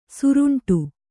♪ suruṇṭu